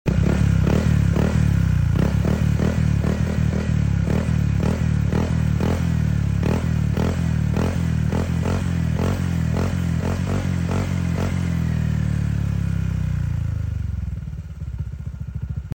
RS8 pipe for ADV 160 sound check